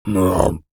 Male_Grunt_Hit_11.wav